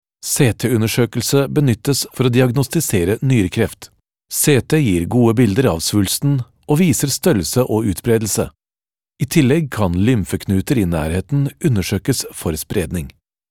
movie deep male experience voice commercial tv radio studio language norwegish norwegian trustworthy
Sprechprobe: Industrie (Muttersprache):